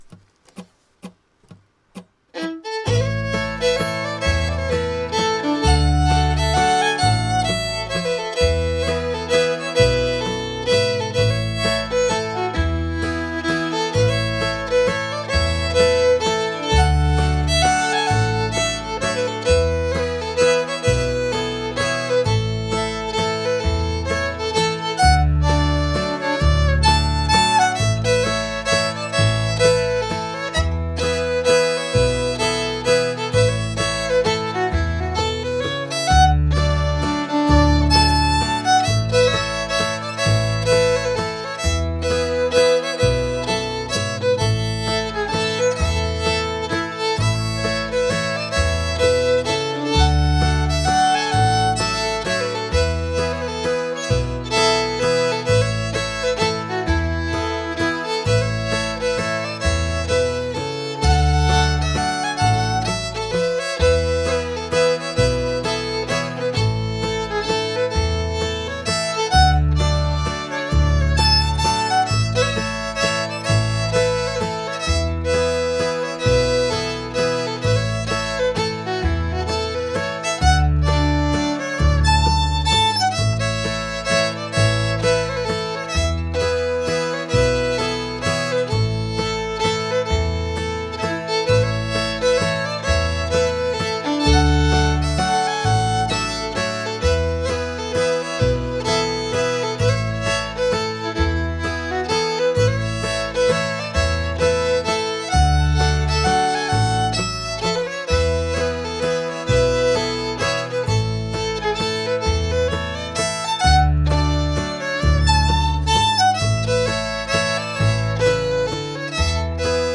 Waltz – Washington Old Time Fiddlers Association